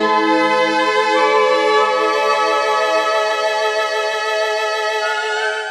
Synth Lick 50-09.wav